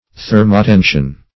Search Result for " thermotension" : The Collaborative International Dictionary of English v.0.48: Thermotension \Ther`mo*ten"sion\, n. [Thermo- + tension.]